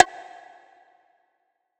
PERC (93).wav